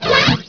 SpellChange.wav